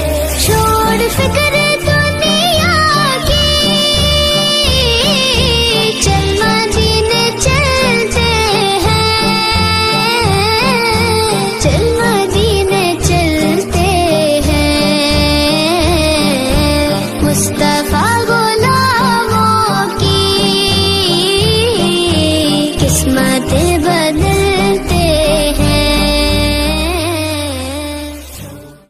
Category: Naat Ringtones